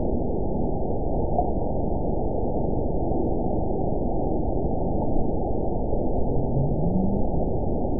event 912183 date 03/20/22 time 05:10:59 GMT (3 years, 1 month ago) score 9.58 location TSS-AB03 detected by nrw target species NRW annotations +NRW Spectrogram: Frequency (kHz) vs. Time (s) audio not available .wav